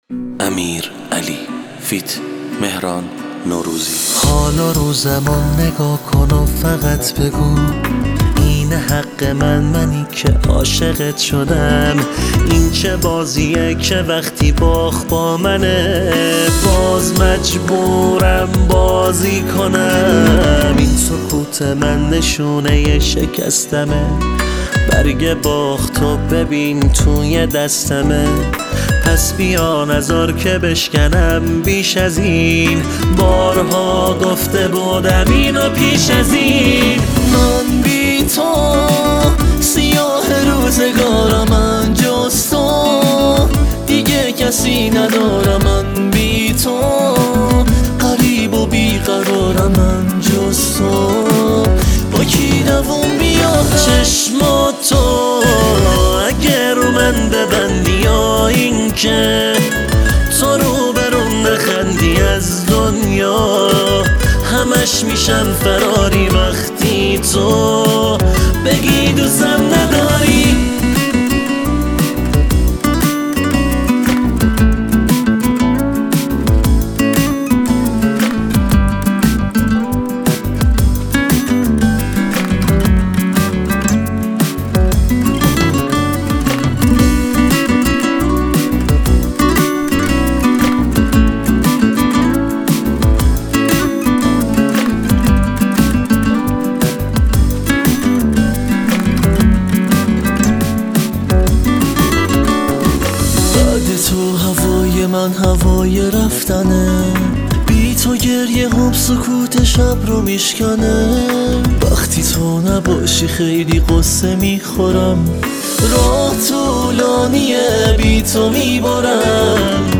گیتار